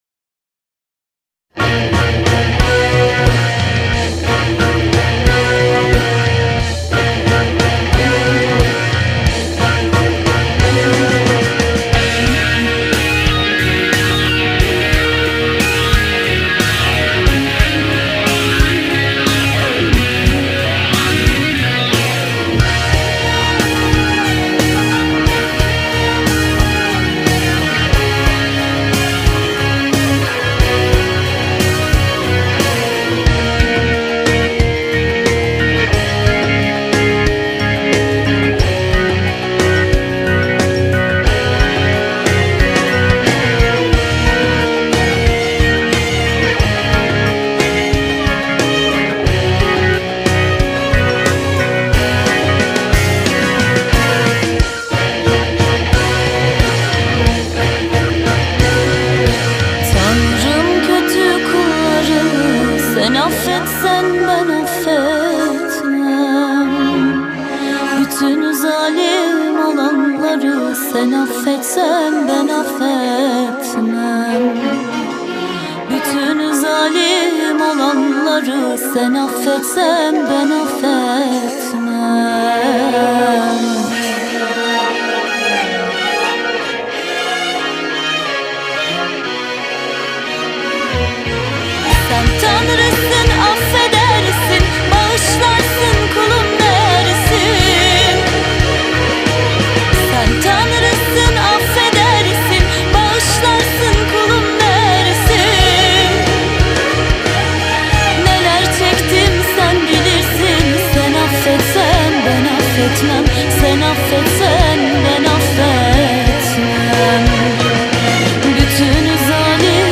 dizi müziği, duygusal hüzünlü rahatlatıcı şarkı.